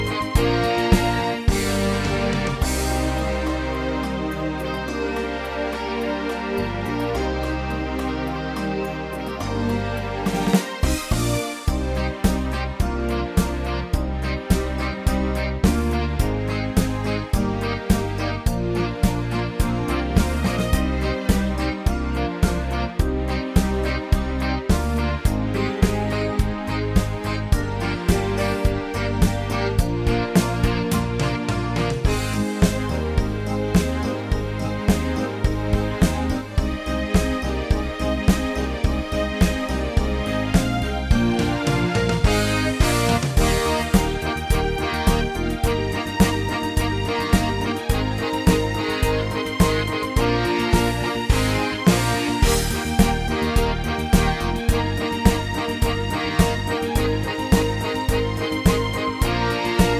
Instrumental MP3 version